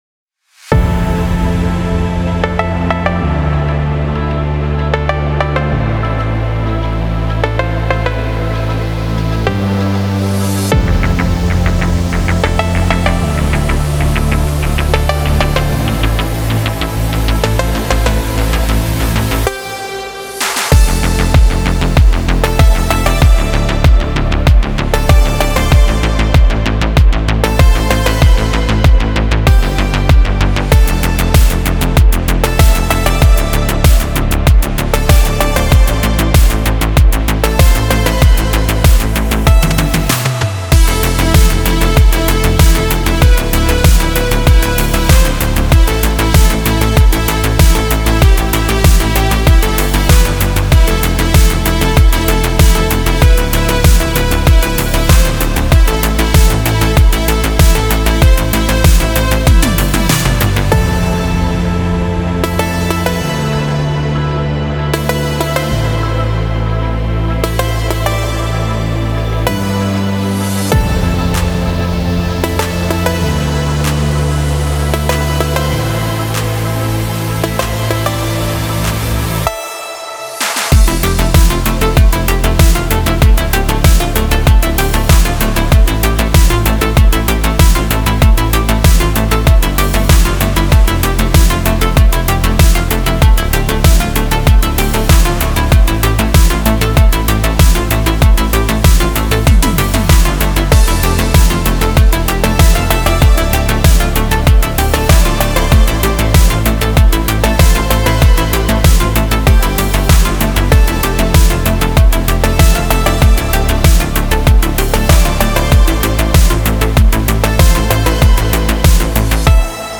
Фонк музыка
Phonk